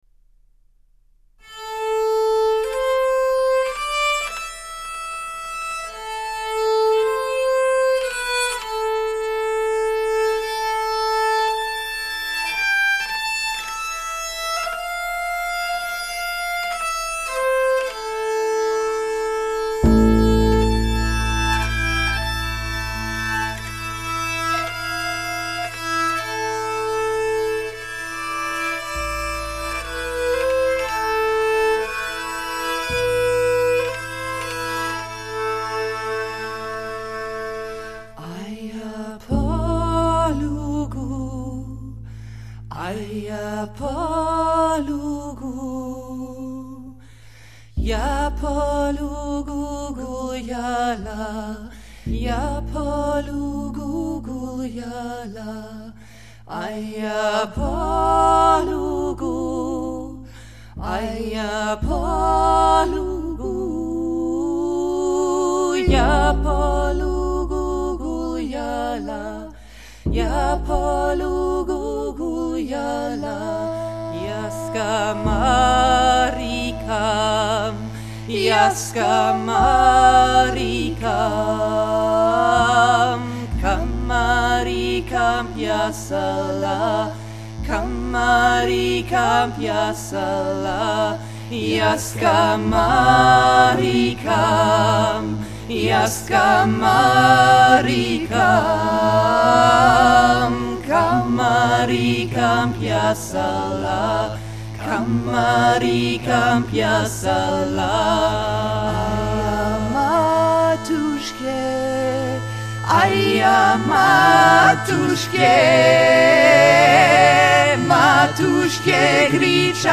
Dudelsack